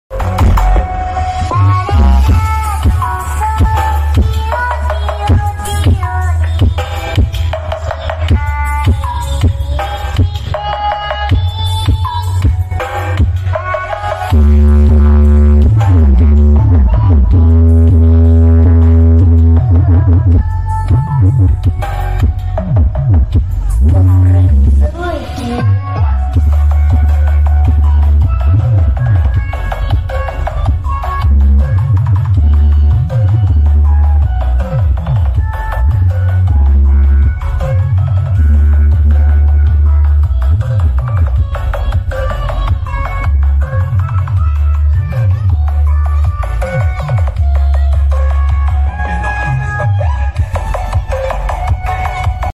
karnaval kemiri 2025